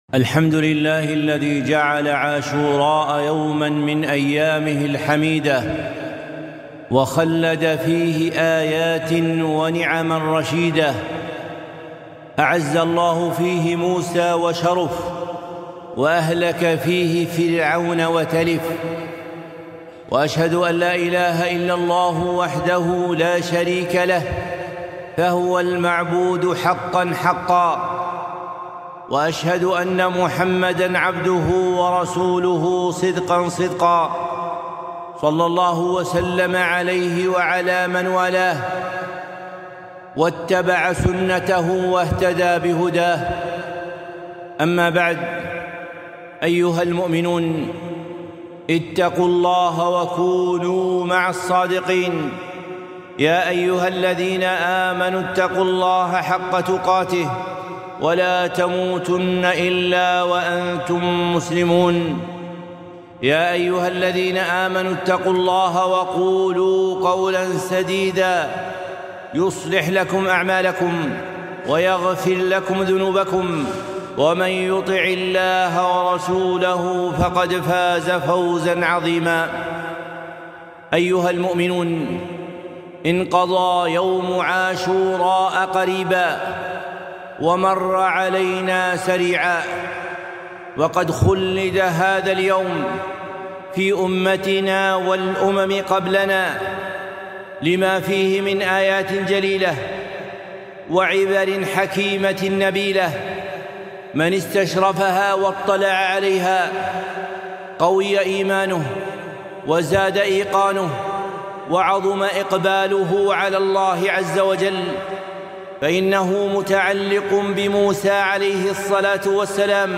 خطبة - دعاء صاحب عاشوراء ١٤ المحرم ١٤٤٤ - دروس الكويت